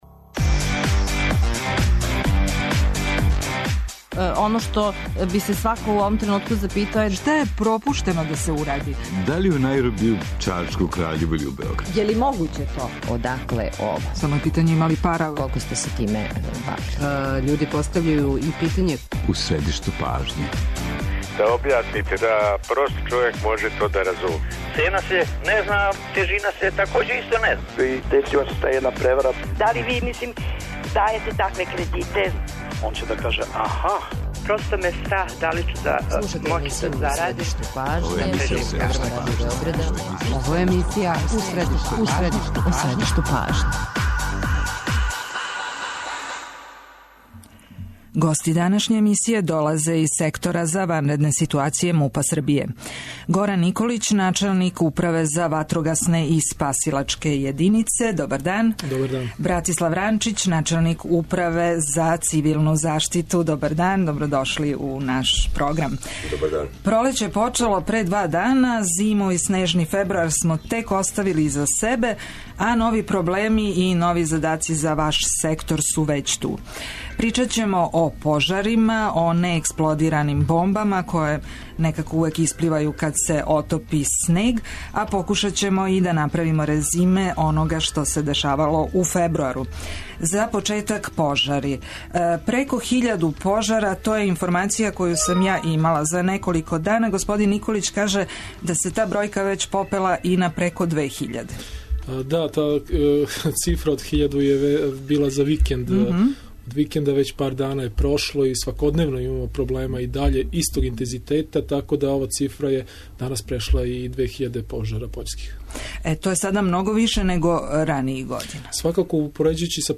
Да ли ће казне и до 100 000 динара спречити људе да спаљују отпад, суву траву, коров и остало? Гости емсије су Горан Николић, начелник Управе за ватрогасне и спасилачке јединице и Братислав Ранчић, начелник Управе за цивилну заштиту Сектора за ванредне ситуације МУП-а Србије.